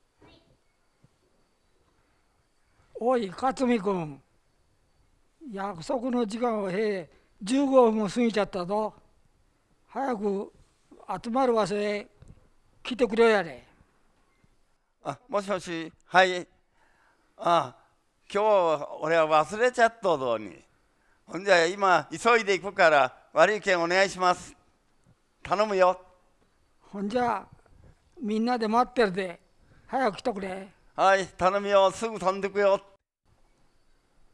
会話（ロールプレイ） ─雨畑─
[2] すぎちゃったぞ：「ぞ」は [ðo] か。「ど」[do] に近く聞こえる。